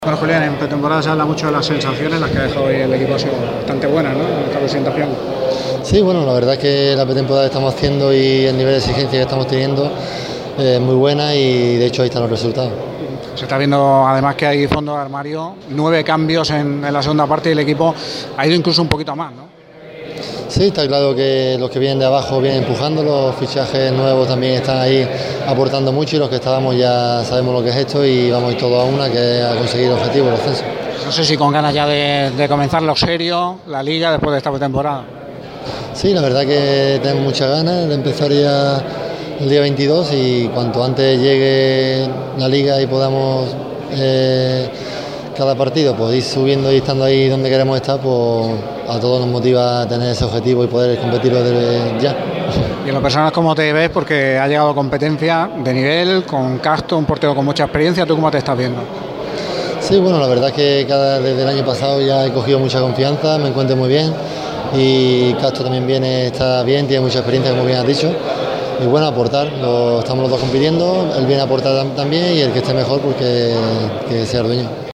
JULIAN CUESTA ENTREVISTA